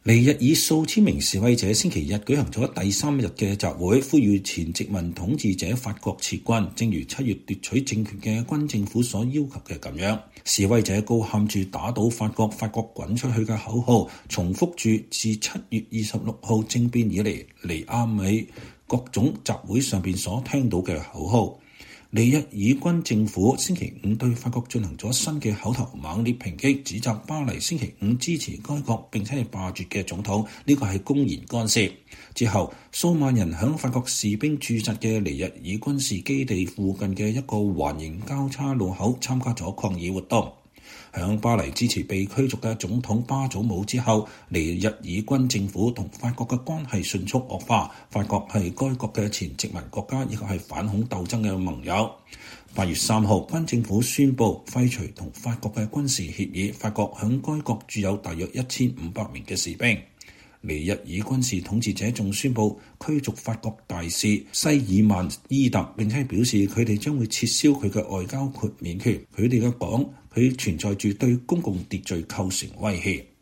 2023年9月3日，尼日爾國家國土安全保障委員會（CNSP）的支持者在尼亞美一個駐紮法國軍隊的空軍基地外，要求法軍離開尼日爾。
示威者高喊著“打倒法國！法國滾出去”的口號，重複著自7 月26 日政變以來尼亞美各種集會上聽到的口號。